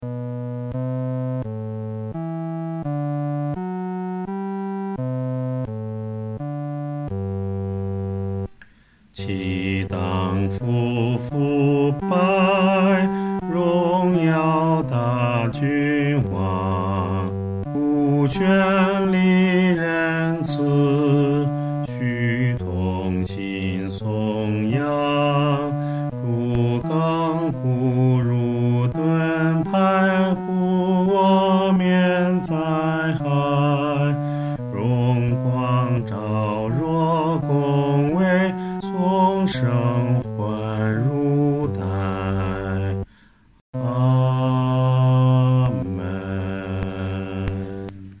合唱
男低
本首圣诗由石家庄圣诗班（二组）录制